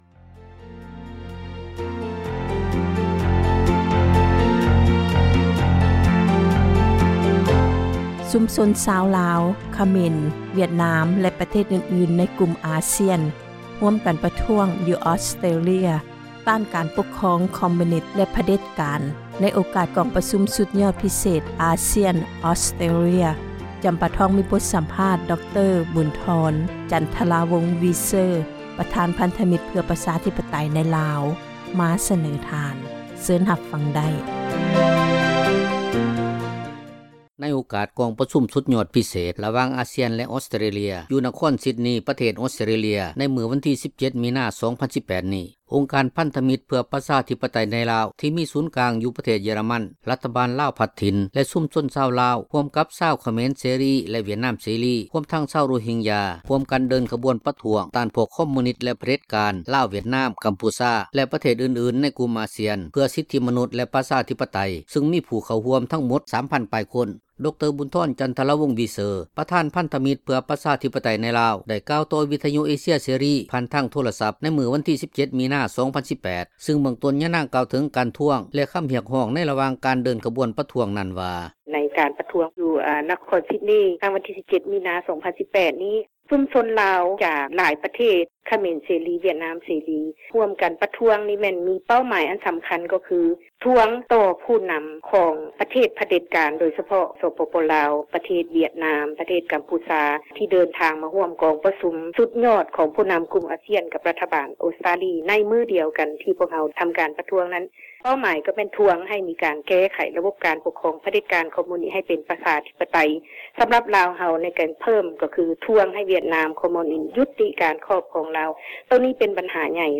ໃຫ້ສັມພາດ ວິທຍຸ ເອເຊັຽ ເສຣີ ຜ່ານທາງໂທຣະສັບ ໃນມື້ ວັນທີ 17 ມິນາ 2018
(ສຽງສັມພາດ)